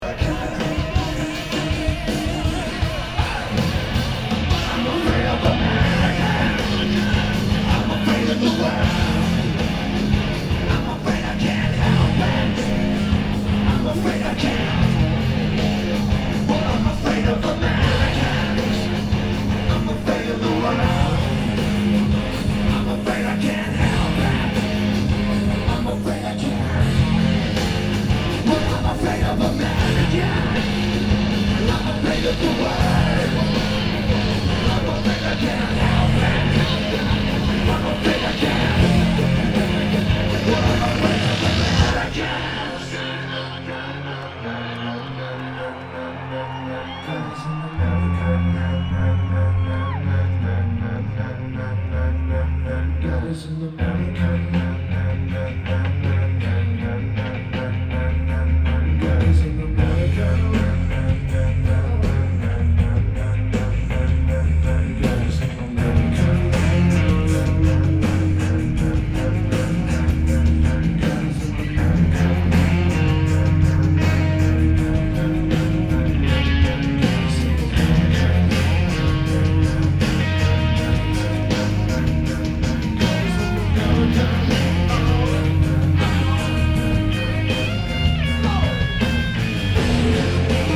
Cricket Wireless Amphitheater
Drums
Bass
Vocals/Guitar/Keyboards
Lineage: Audio - AUD (CA-11 + Church Audio Preamp + R-09)